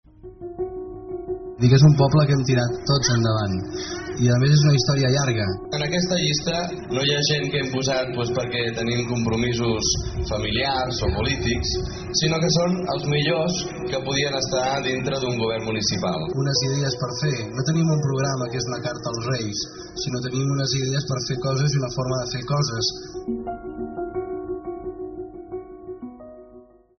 La campanya ha publicat un vídeo amb fragments de veu de Valentí Agustí en els seus primers anys com alcalde, com els que sentíem, i ha fet durant aquesta campanya electoral una crida a la ciutadania per compartir arxius de veu, imatges o vídeos que, segons expliquen els impulsors, es van afegir en un document final que es va poder veure al darrer míting de la campanya electoral del PSC, partit que Agustí ha liderat en els últims 36 anys.